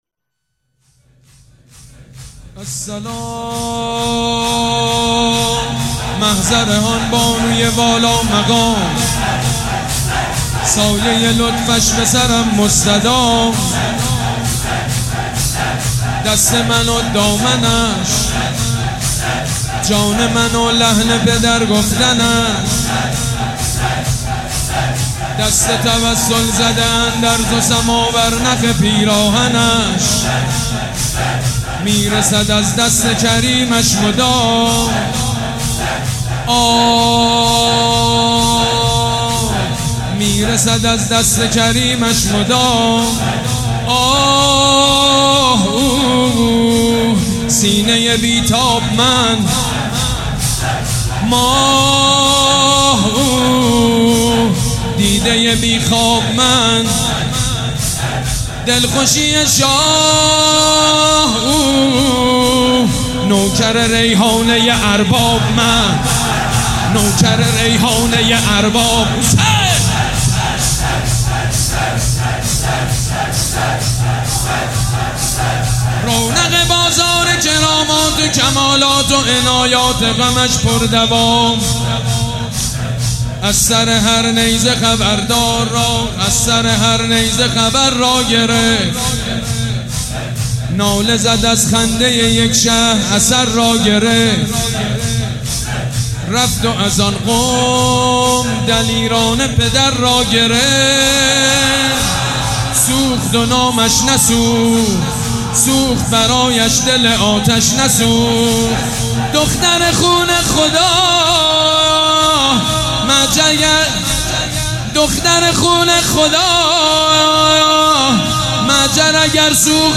تهران- الکوثر: گزیده مداحی سید مجید بنی فاطمه در شب سوم محرم 98